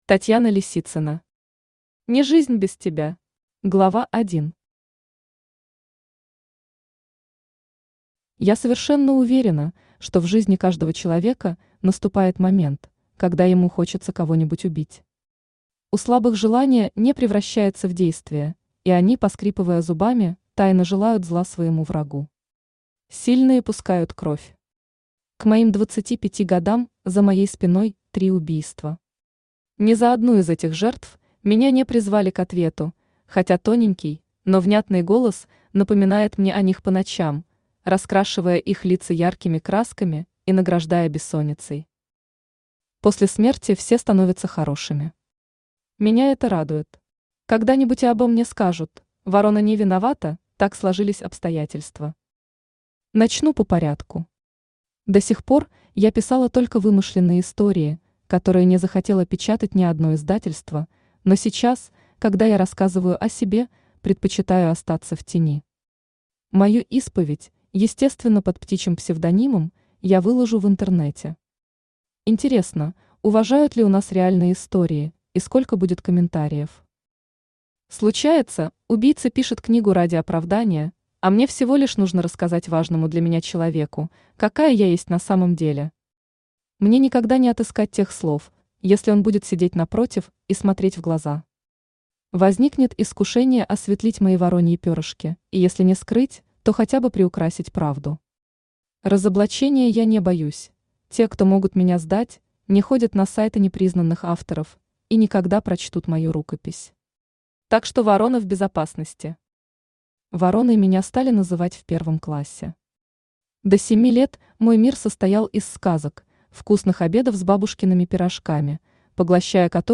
Аудиокнига Не жизнь без тебя | Библиотека аудиокниг
Aудиокнига Не жизнь без тебя Автор Татьяна Лисицына Читает аудиокнигу Авточтец ЛитРес.